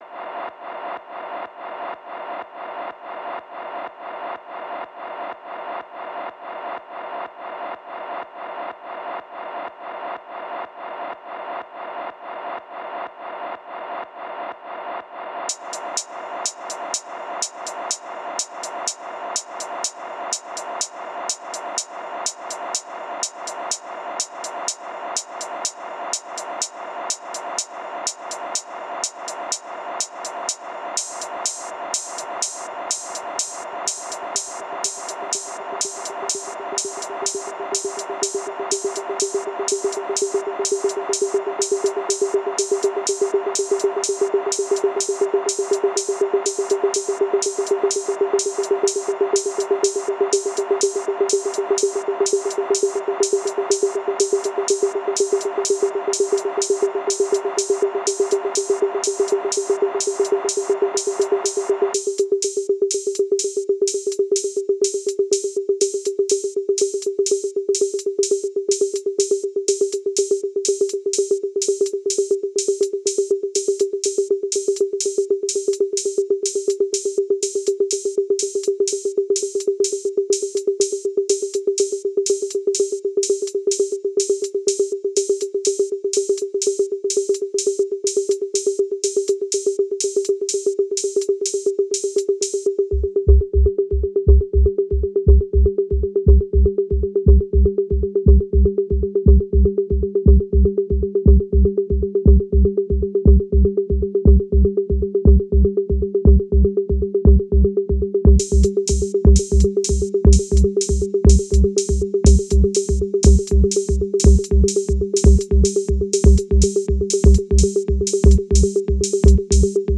Große Moschee in Bobo-Dioulasso, Burkina Faso